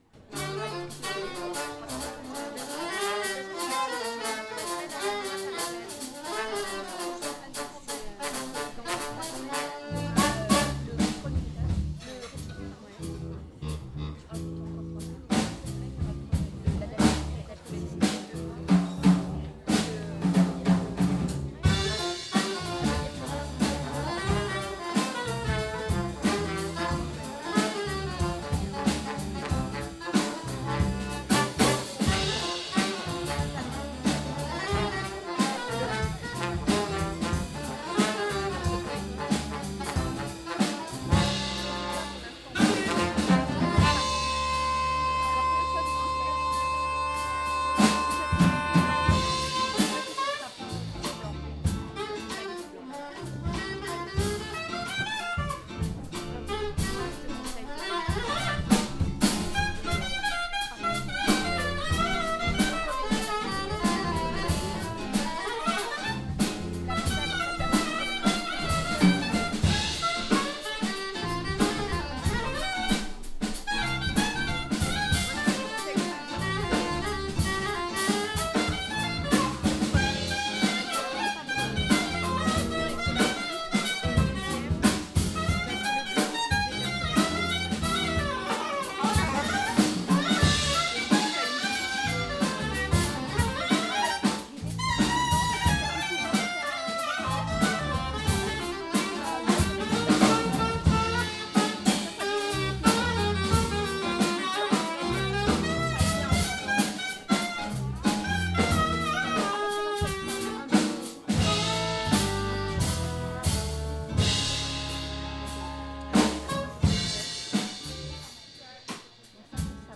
· Genre (Stil): Jazz
· Kanal-Modus: stereo · Kommentar